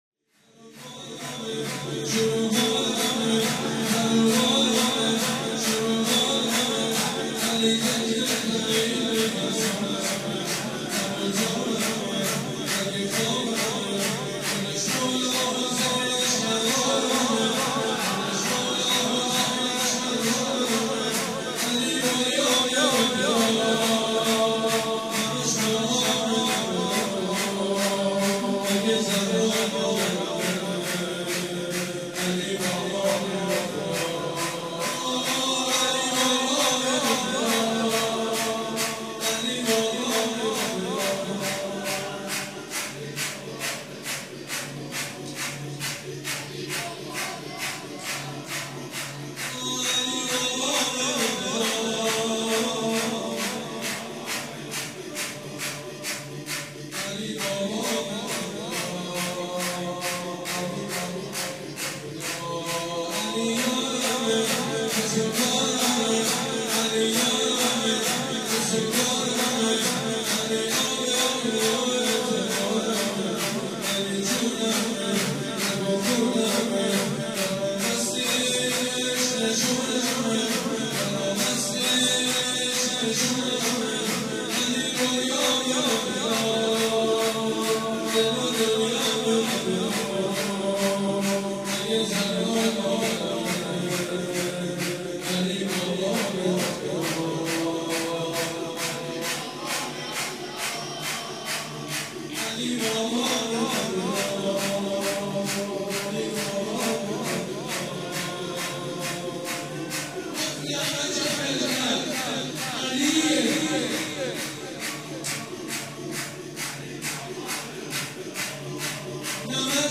زمینه، روضه، مناجات